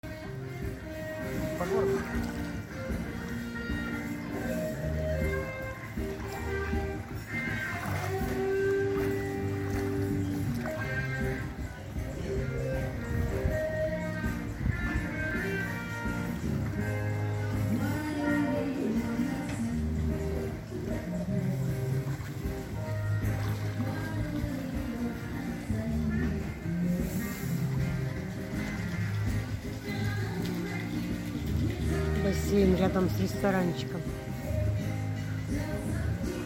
В Тае, в ресторанчике у моря, по вечерам играл маленький дуэт. Гитара, скрипка, вокал ну и электроника в помощь... В основном англоязычные блюзы.